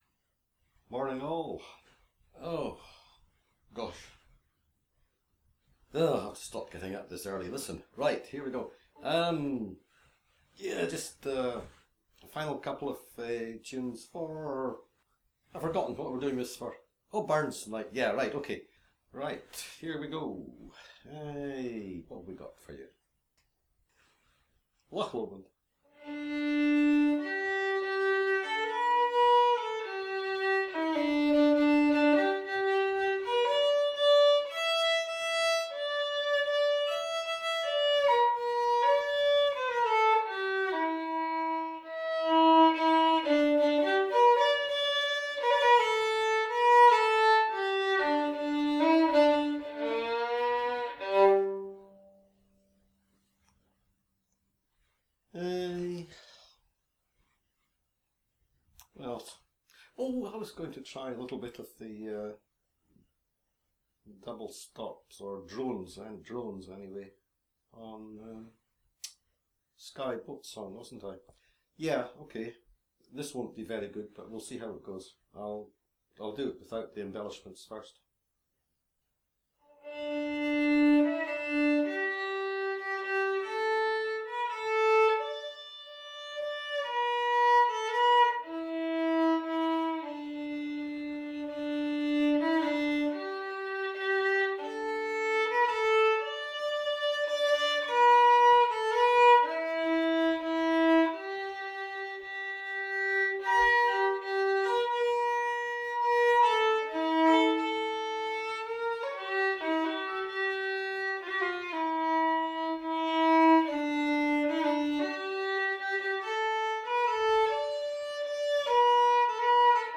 Here's a last couple of tunes - a wee bit of Loch Lomond and Skye Boat Song with some attempts at playing open string drone on SBS.